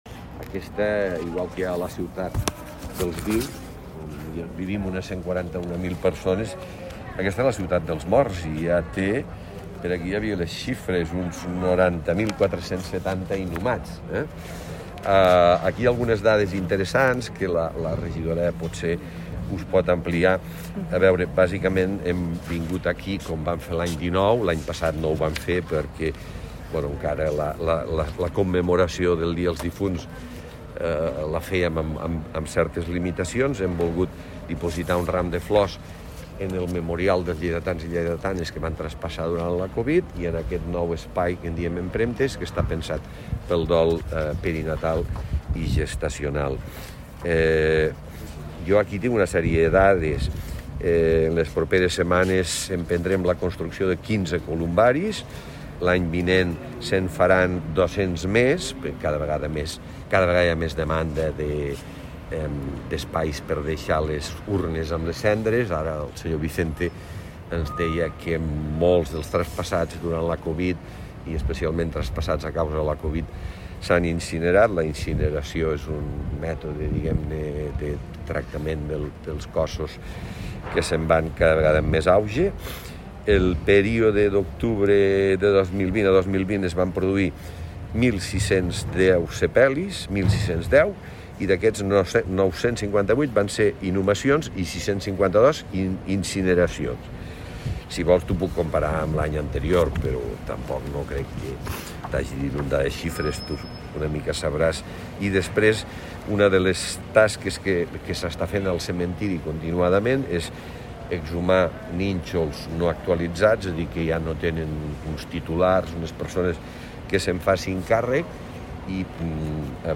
tall-de-veu-miquel-pueyo